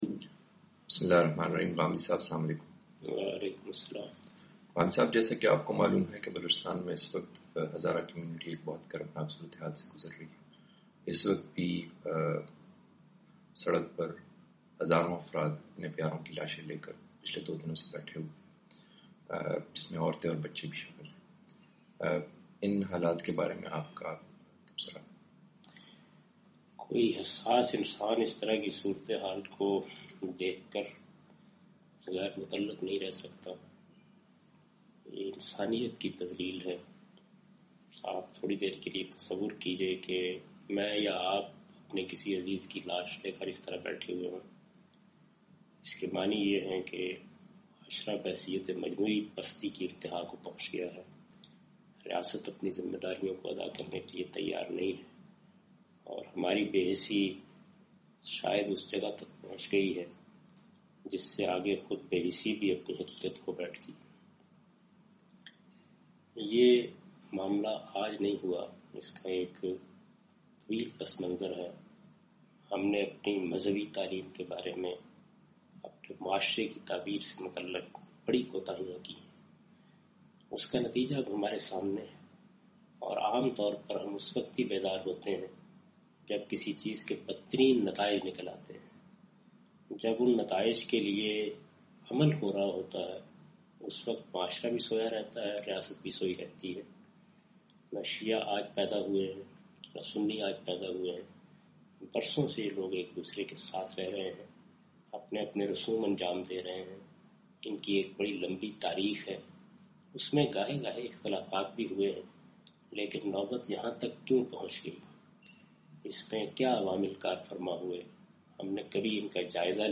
Javed Ahmad Ghamidi's talk on the tragedy of Quetta Blasts and Target Killing of innocent lives.